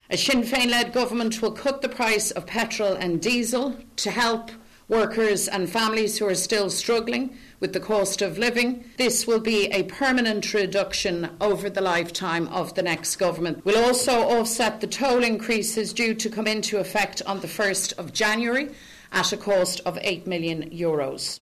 Sinn Féin leader Mary Lou McDonald says they will also reverse the planned increases on tolls.